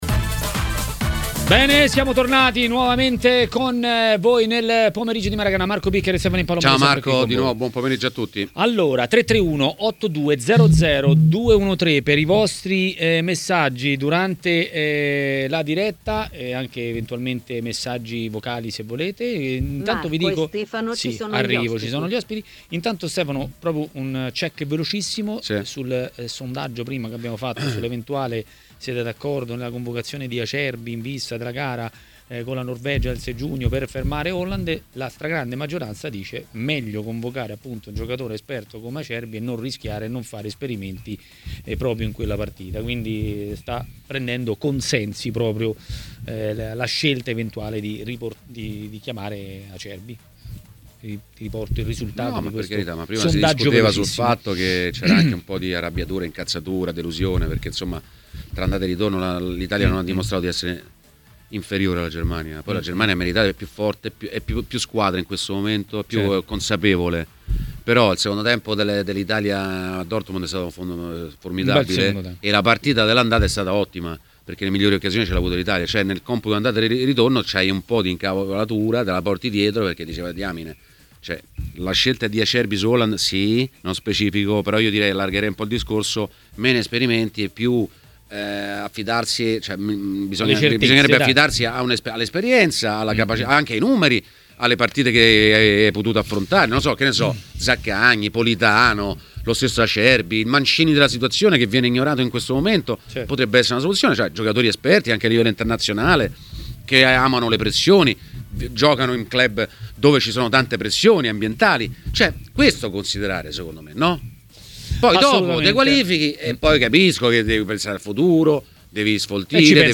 L'ex calciatore Lionello Manfredonia è stato ospite di TMW Radio, durante Maracanà.